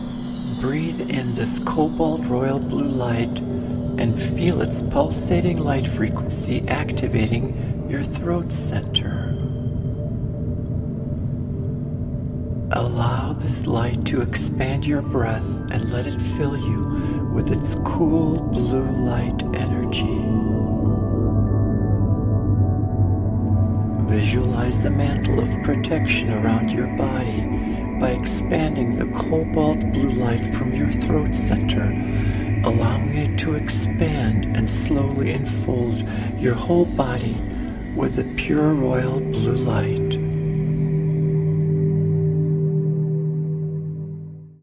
AN ADVANCED GUIDED MEDITATION